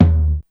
• Tom Sound D# Key 01.wav
Royality free tom single hit tuned to the D# note. Loudest frequency: 161Hz
tom-sound-d-sharp-key-01-x9o.wav